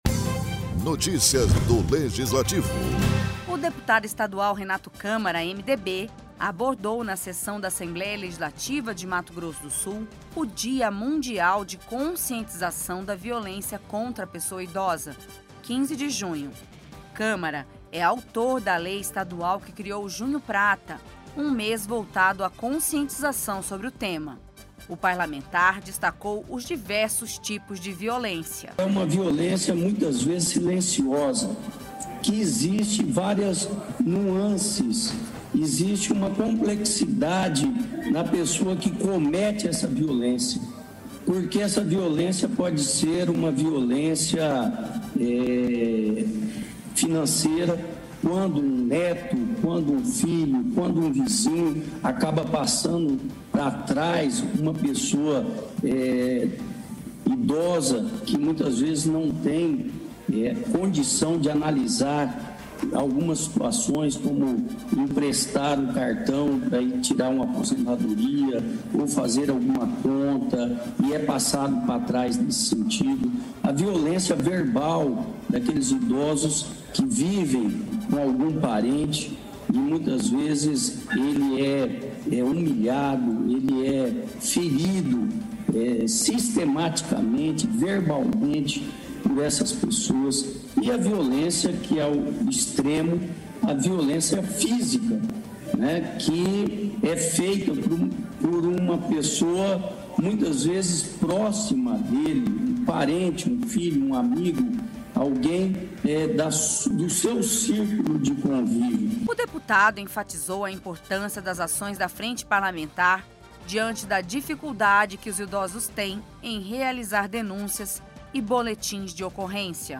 O Deputado Estadual Renato Câmara (MDB) abordou na Sessão da Assembleia Legislativa de Mato Grosso do Sul, o Dia Mundial de Conscientização da Violência contra a Pessoa Idosa, 15 de Junho.